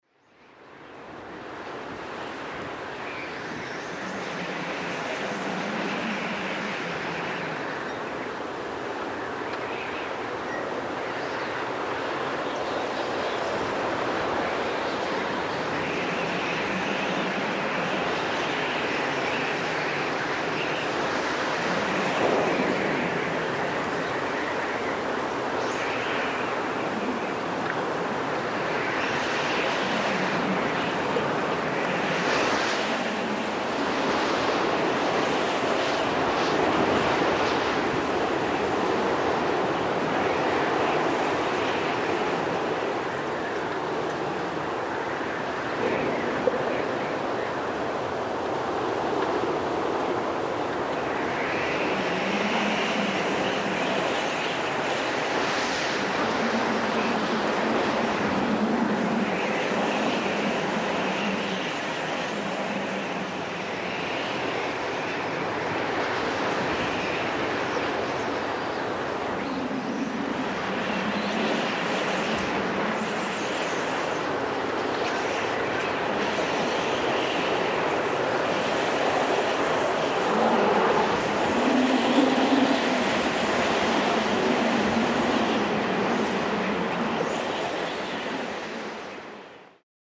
Звуки песчаной бури
На этой странице собраны звуки песчаной бури – от далёкого гула до оглушительного вихря.